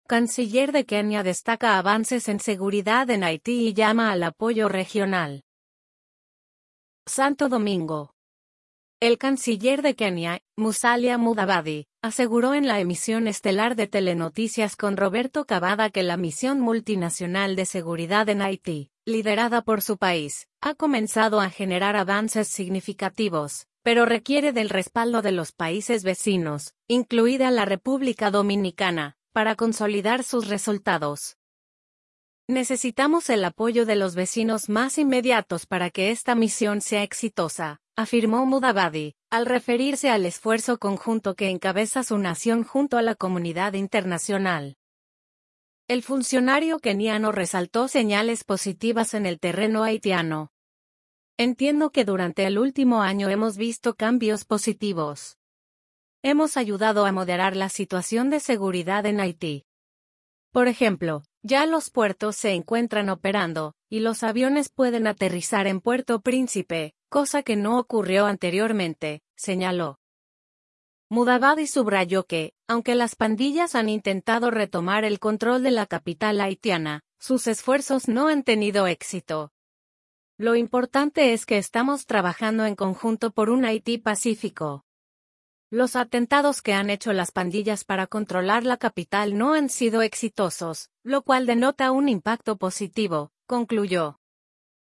SANTO DOMINGO.– El canciller de Kenia, Musalia Mudavadi, aseguró en la emisión estelar de Telenoticias con Roberto Cavada que la misión multinacional de seguridad en Haití, liderada por su país, ha comenzado a generar avances significativos, pero requiere del respaldo de los países vecinos, incluida la República Dominicana, para consolidar sus resultados.